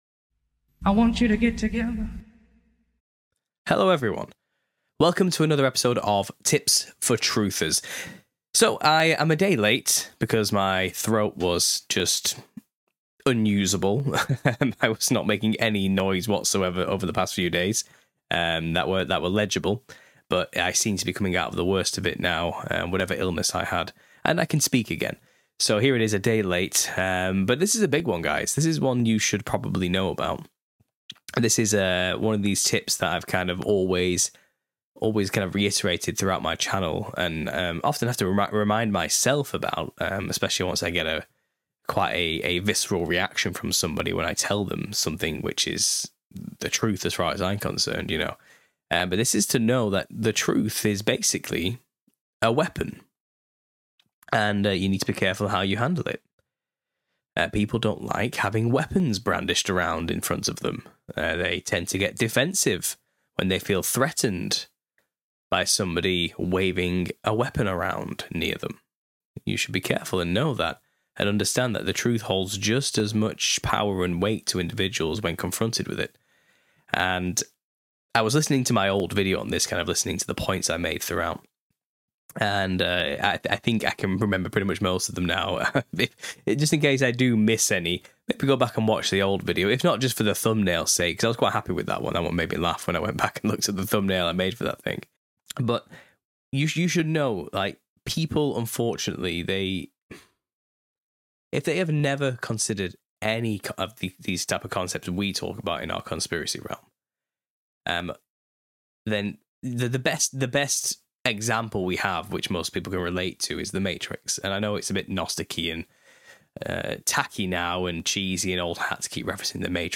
Live Q&A - 53 - Dire Wolves & Robots With Guns – Understanding Conspiracy – Podcast